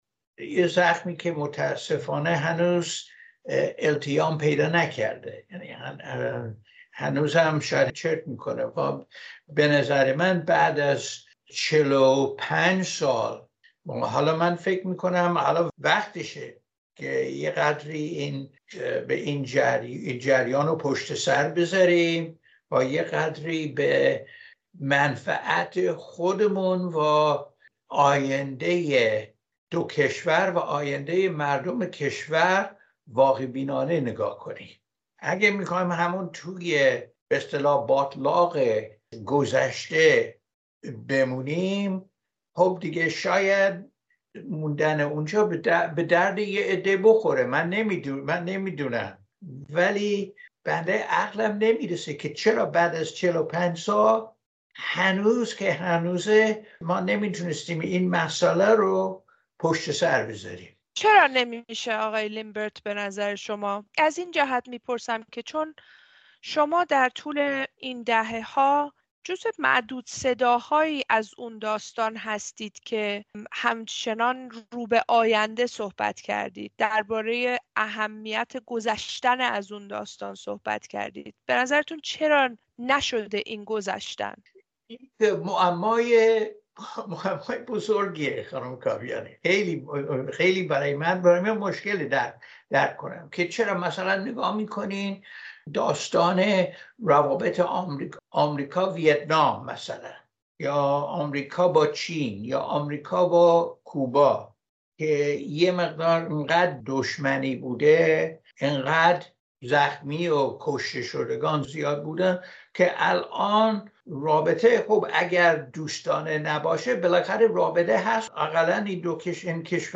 در چهل‌وپنجمین سالگرد اشغال سفارت آمریکا در تهران، رادیوفردا با جان لیمبرت دیپلمات پیشین و یکی از گروگان‌های آمریکایی گفت‌وگو کرده است.
گفت‌وگو با جان لیمبرت دربارهٔ تجربهٔ گروگانگیری و آینده روابط ایران و آمریکا